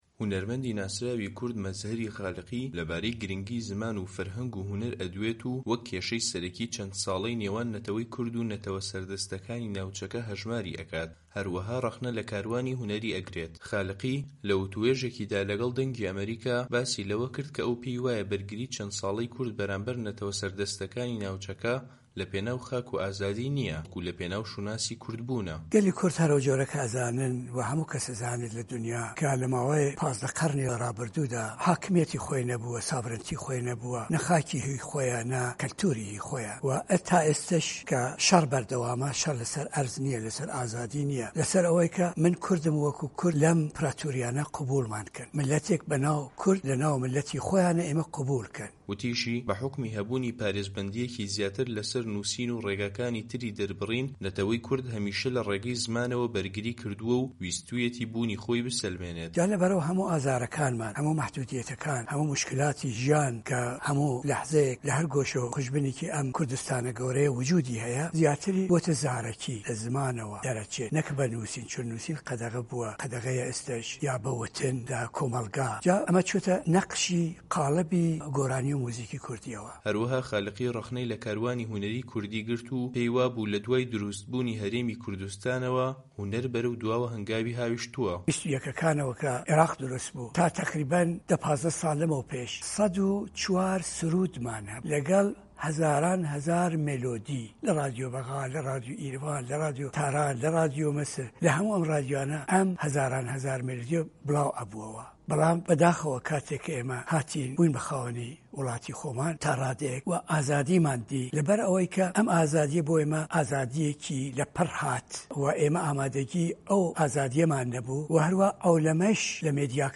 وتووێژ لەگەڵ مەزهەری خالقی